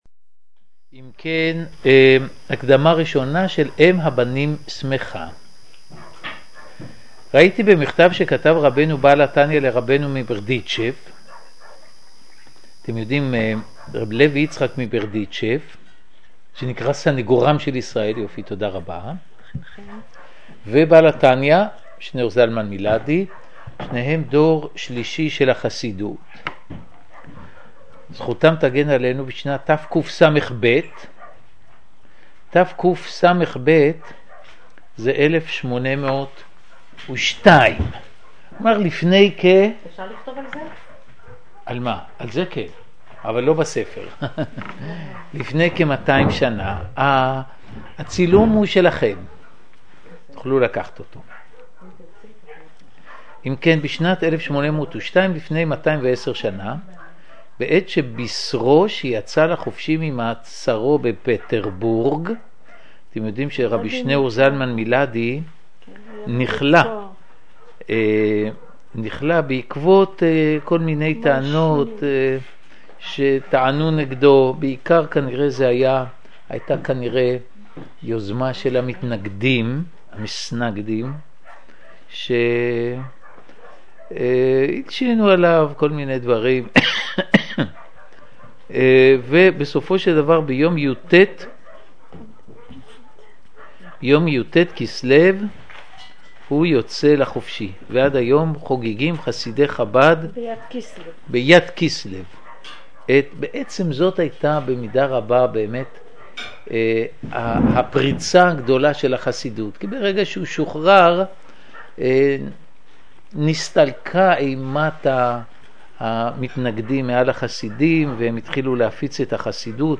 מדרשת נביעה בבית חגלה מציעה יום עיון, כל שלושה שבועות, בימי ראשון בין 16:00 ל-20:00.
המרצים וכן המשתתפים כולם היו נרגשים מן השיעורים וגם המעמד, שהרי כולנו צועדים לקראת מציאות חדשה של תורה ישראלית הצומחת ממעמקי האדמה ועמה הקדושים.